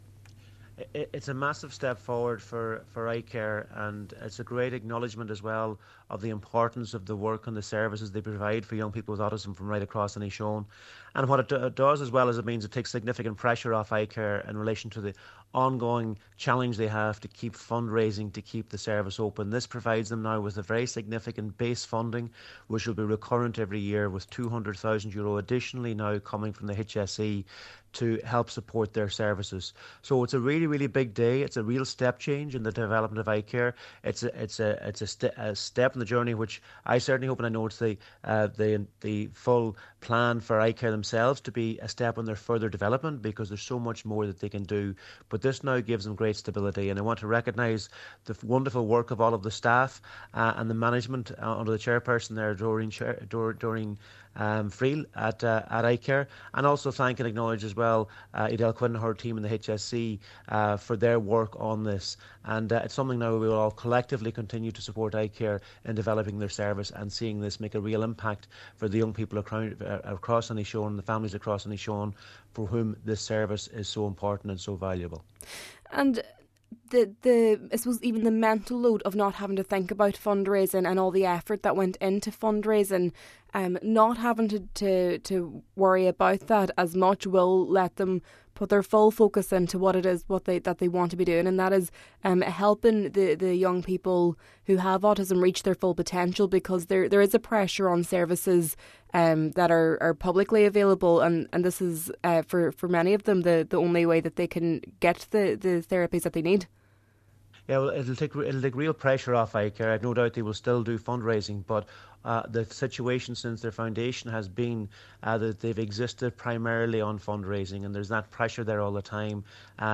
Minister Charlie McConalogue, who supported iCARE in their submission, says the group has relied on fundraising until this point: